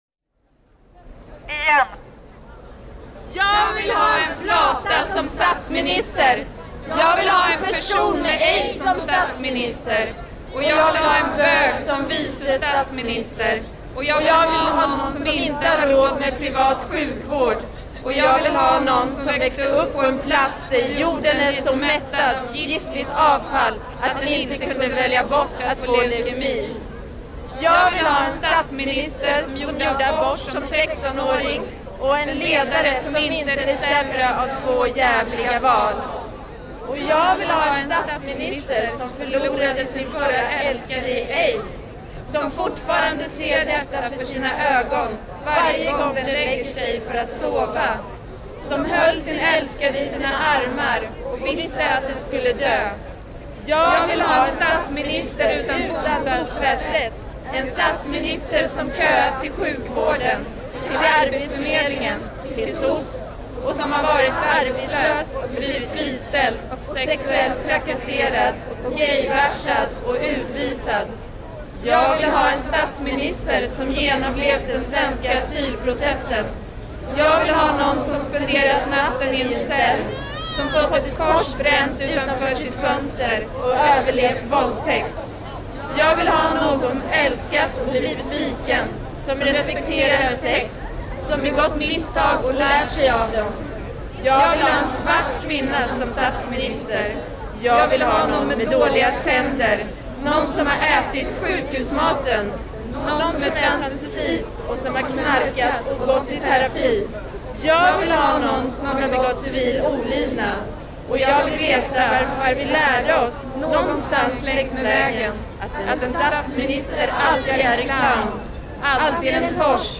Collective reading